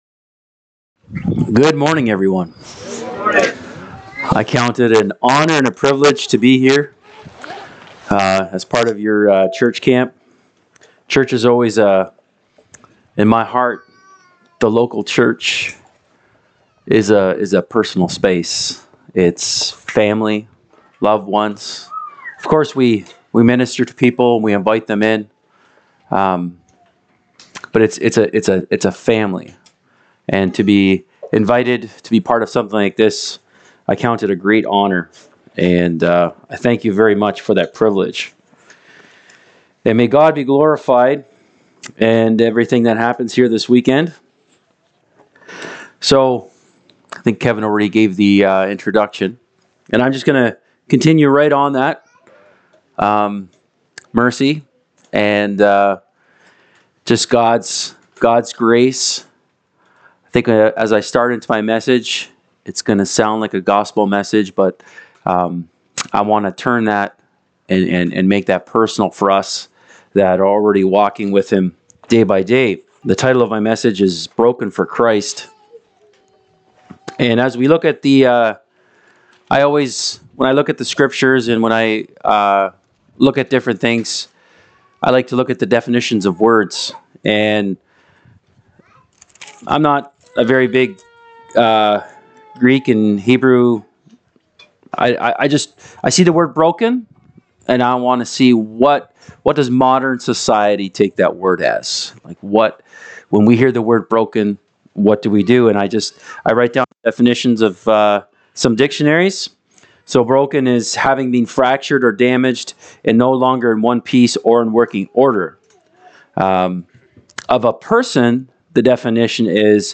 Service Type: Church Camp